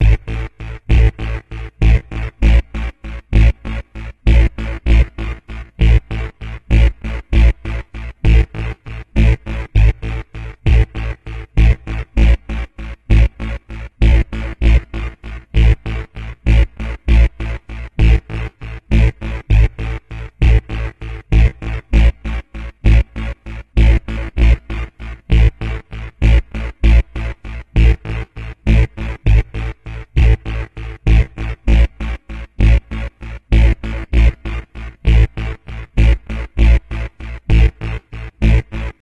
「ベース音」とは、その名の通り、楽曲における繰り返しのリズムを支える重要な楽器です。